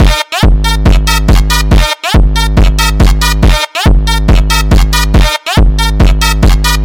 Free Funk Sample Lead Sound Button - Free Download & Play